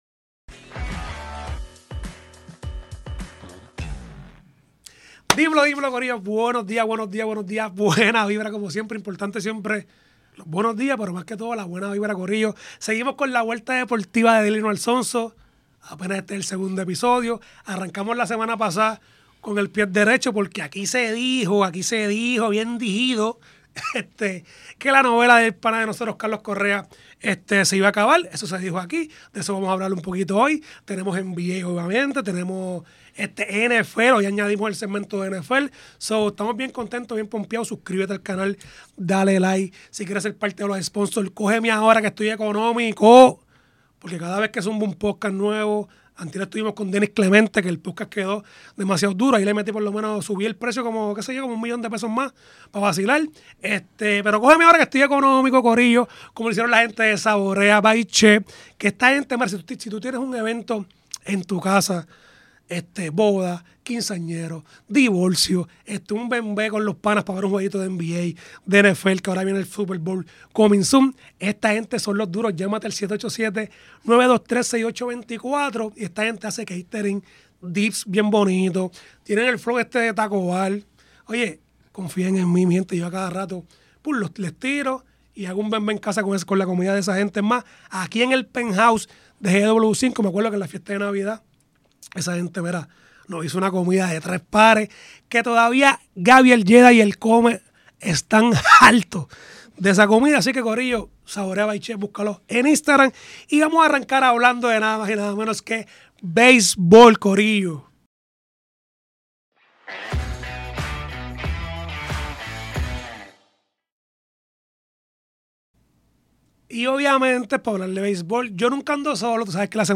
Grabado desde GW-Cinco Studio para GW5 Network.